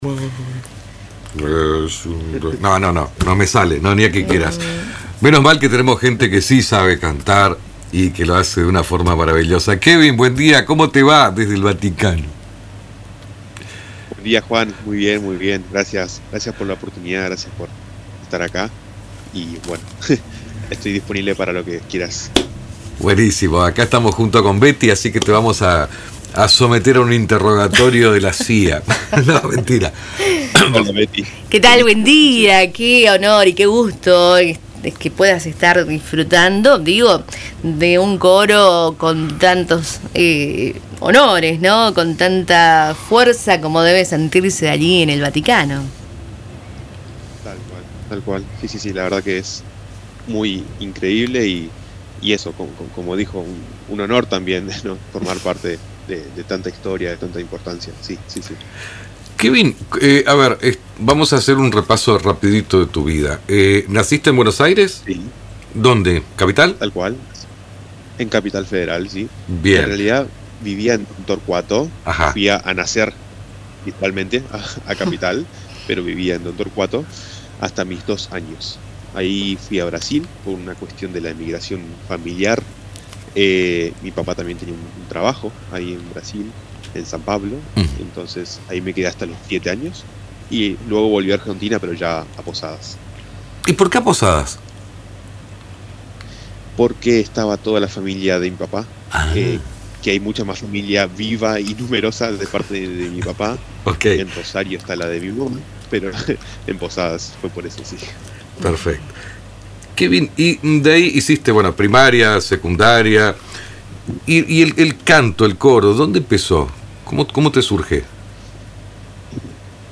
En diálogo con Radio Tupa Mbae